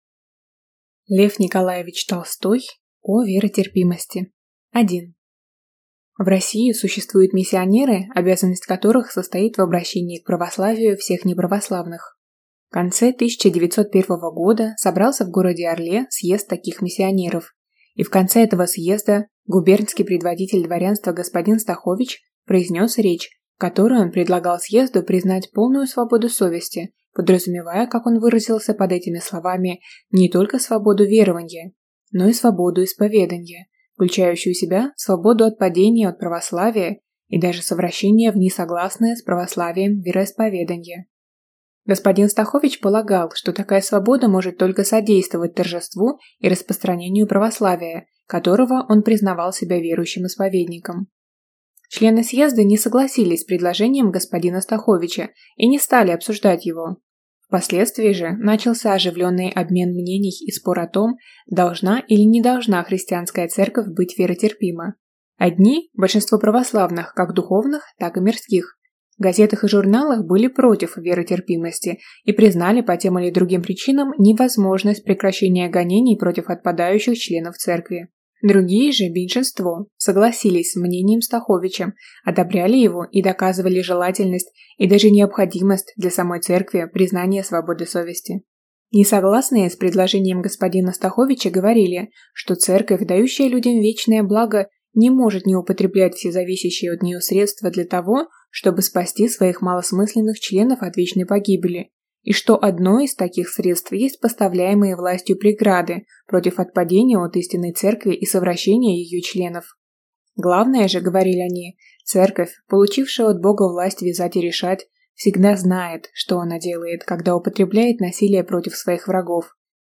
Аудиокнига О веротерпимости | Библиотека аудиокниг
Прослушать и бесплатно скачать фрагмент аудиокниги